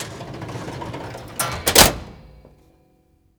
DRAWER MN CL.WAV